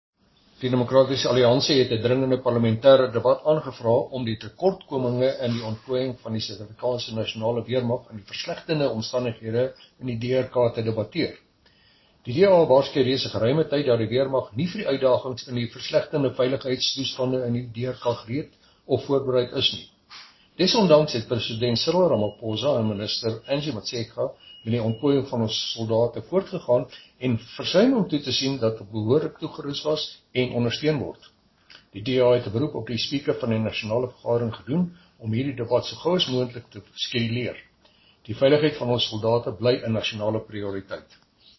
Note to Editors: Please find attached soundbites in
Afrikaans by Chris Hattingh MP.